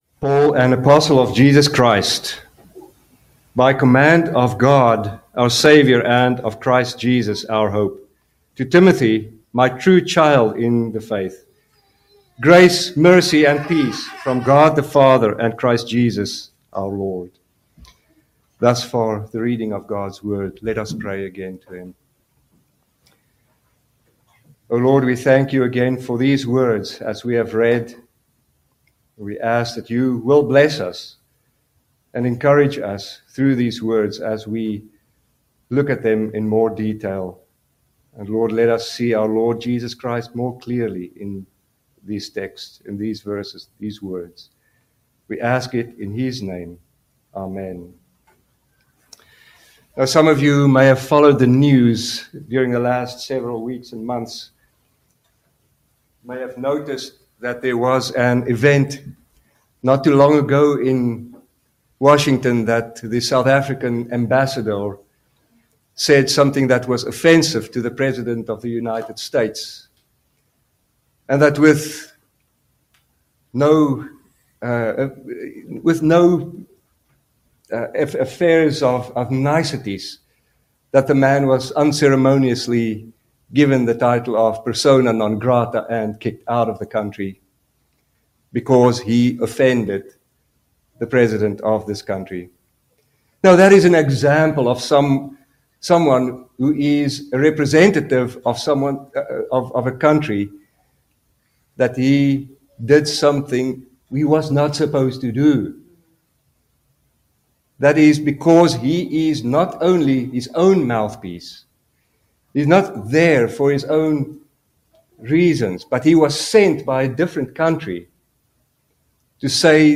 Guest Preachers Passage: 1 Timothy 1:1-2 Service Type: Sunday Evening Service Download the order of worship here .